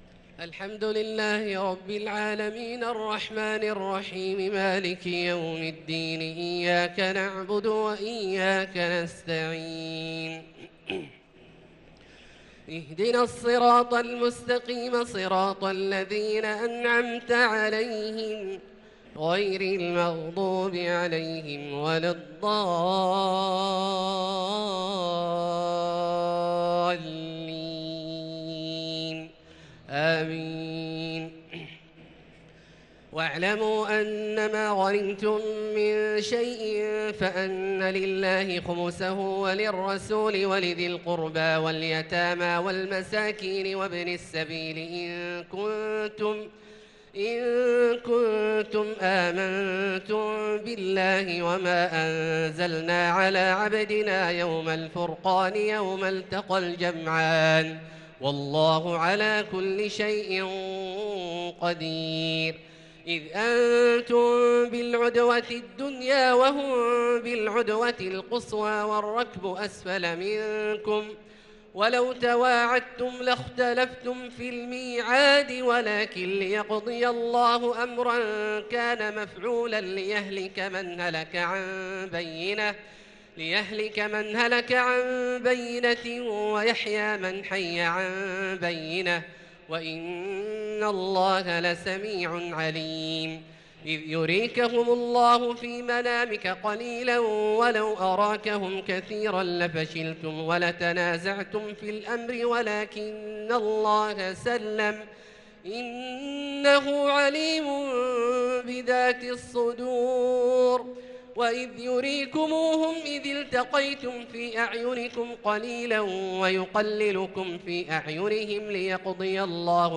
صلاة التراويح ليلة 13 رمضان 1443 للقارئ عبدالله الجهني - الأربع التسليمات الأولى صلاة التراويح
تِلَاوَات الْحَرَمَيْن .